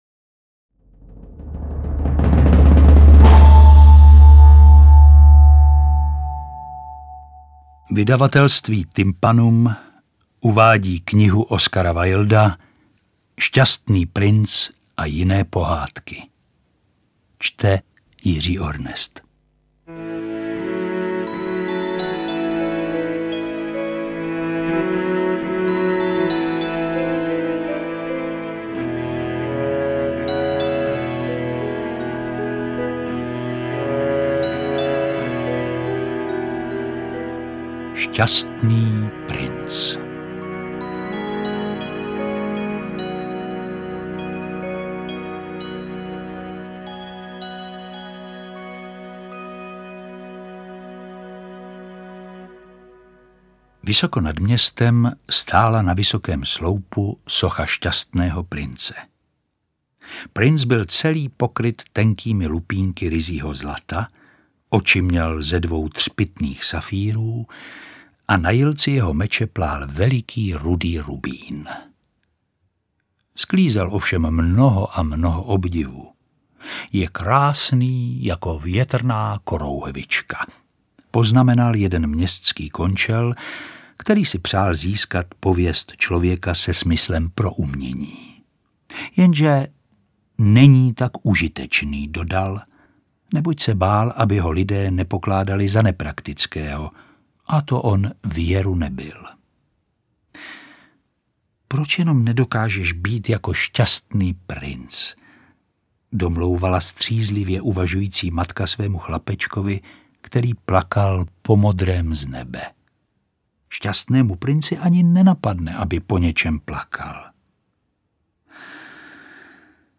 Interpret:  Jiří Ornest
Audiokniha Pohádek Oscara Wilda v podání Jiřího Ornesta ve formátu MP3.